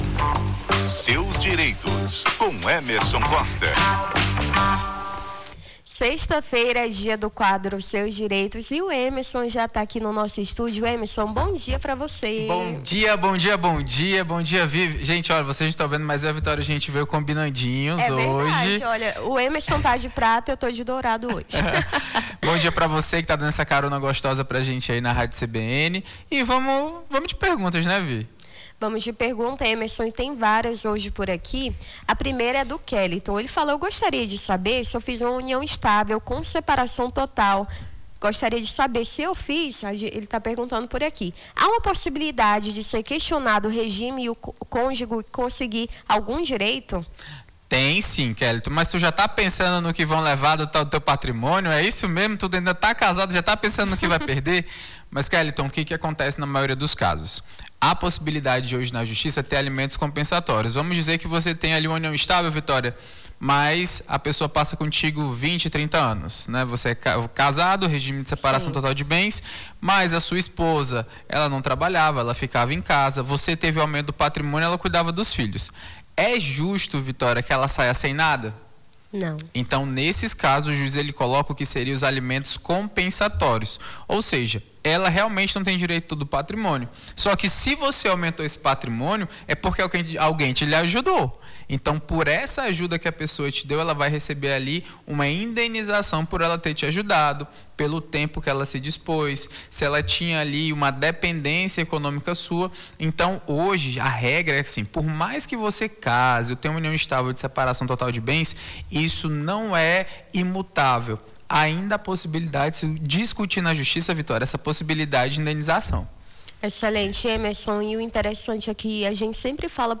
AO VIVO: Confira a Programação
Seus Direitos: advogado tira dúvidas sobre direito de família